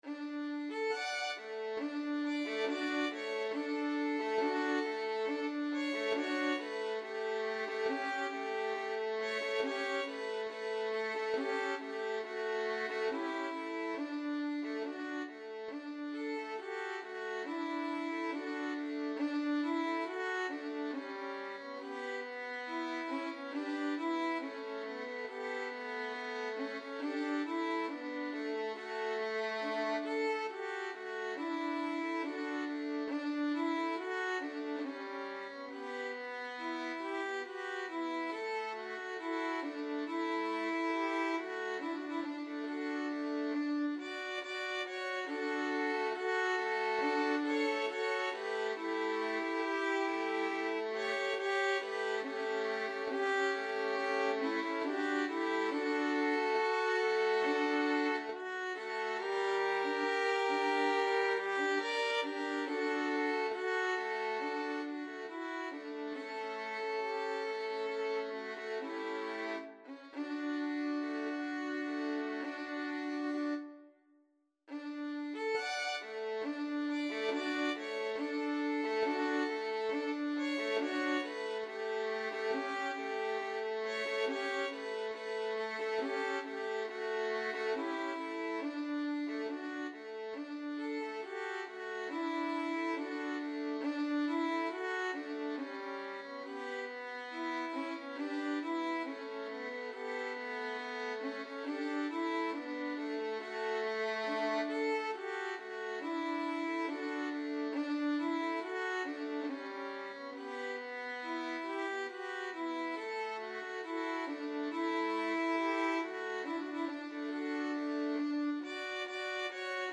Free Sheet music for Violin Quartet
D major (Sounding Pitch) (View more D major Music for Violin Quartet )
Andantino = c. 69 (View more music marked Andantino)
2/4 (View more 2/4 Music)
Violin Quartet  (View more Intermediate Violin Quartet Music)
Classical (View more Classical Violin Quartet Music)